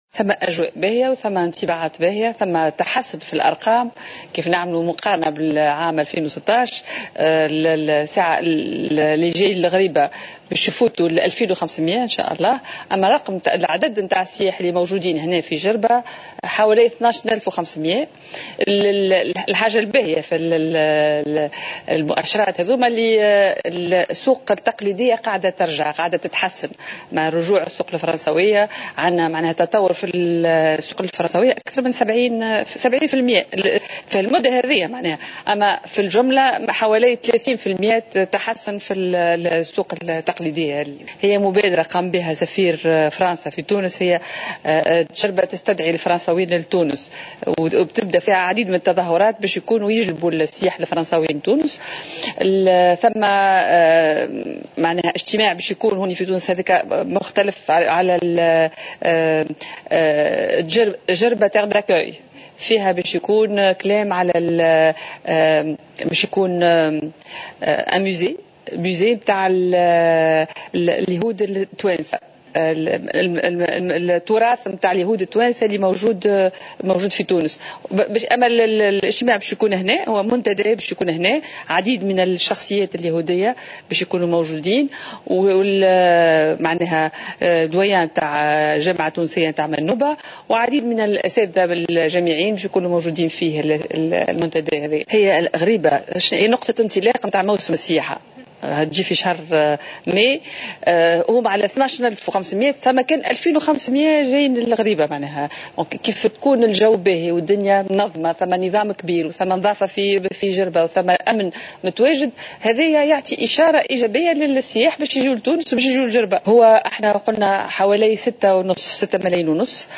وأكّدت وزيرة السياحة سلمى اللومي الرقيق خلالها مداخلتها في نشرة الأخبار على الوطنية الأولى، تسجيل ارتفاع في عدد زوار الغريبة مقارنة بالسنة الماضية الذي سيفوق غدا 2500 زائر، مشيرة إلى أن عدد السياح المتواجدين في جربة فاق 12 الف سائح.